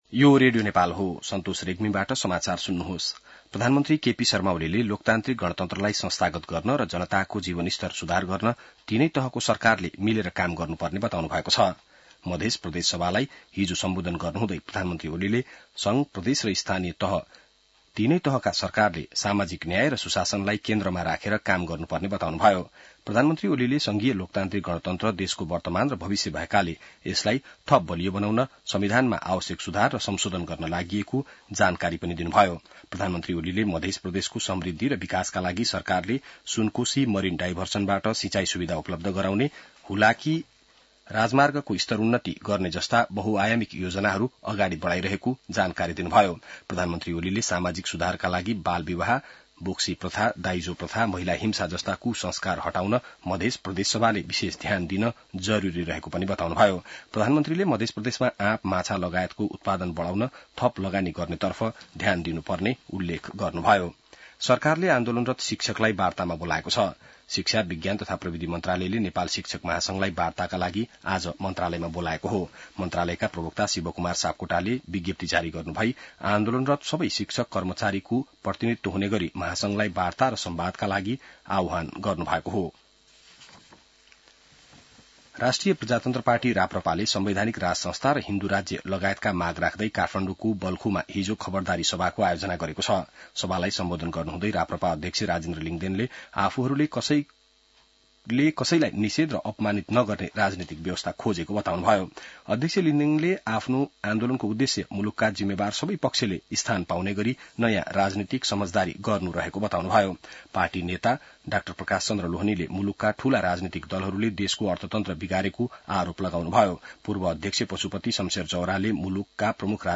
An online outlet of Nepal's national radio broadcaster
बिहान ६ बजेको नेपाली समाचार : २७ चैत , २०८१